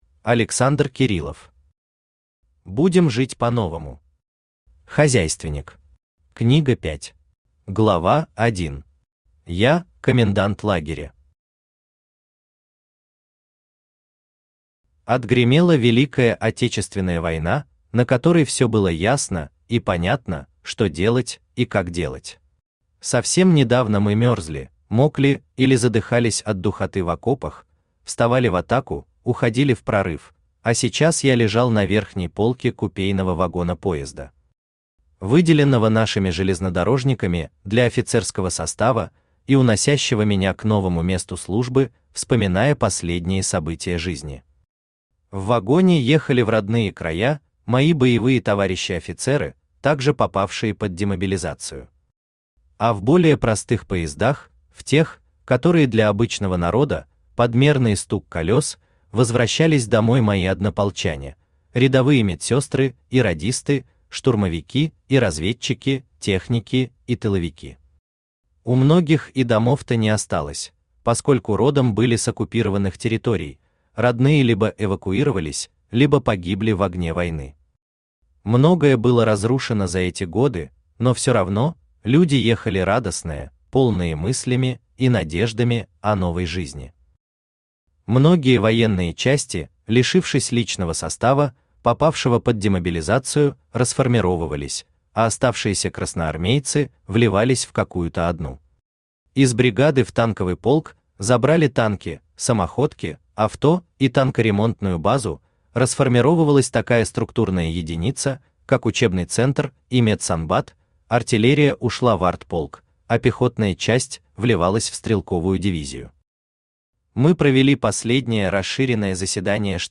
Книга 5 Автор Александр Леонидович Кириллов Читает аудиокнигу Авточтец ЛитРес.